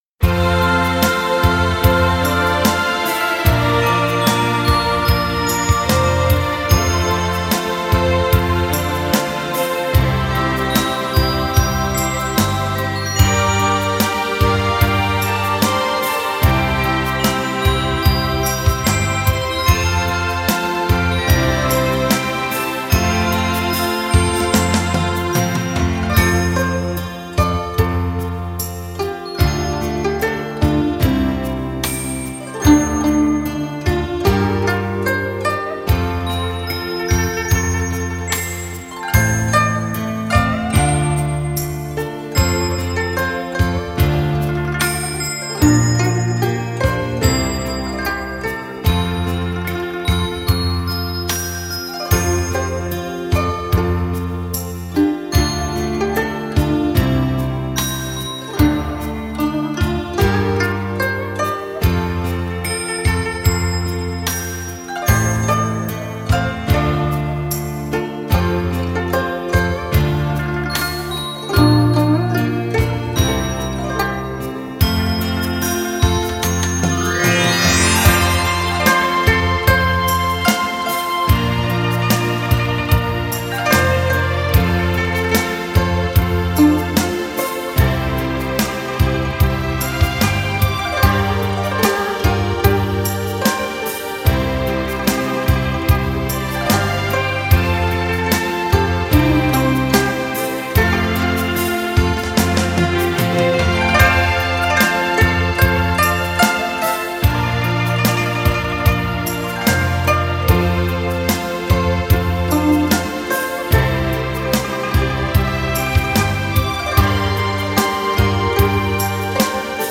古筝音色轻雅秀丽、优美动听，
如微风轻拂，如碧波荡漾，如潺潺流水，又如幻想仙境
采用古筝特有的音色来表现